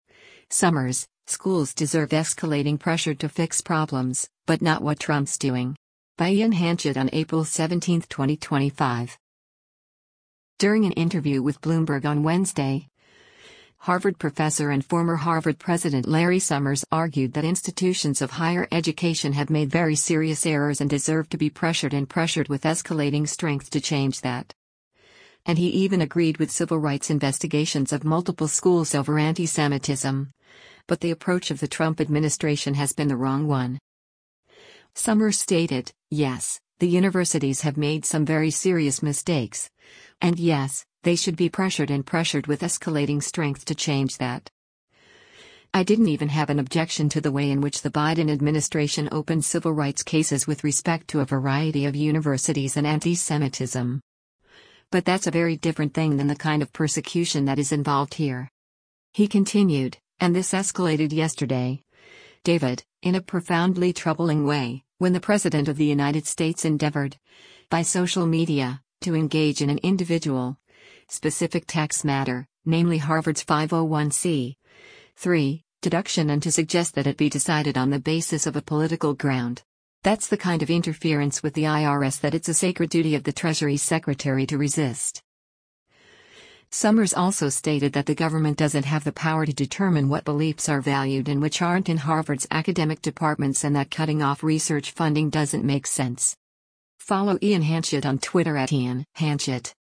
During an interview with Bloomberg on Wednesday, Harvard Professor and former Harvard President Larry Summers argued that institutions of higher education have made “very serious” errors and deserve to “be pressured and pressured with escalating strength to change that.”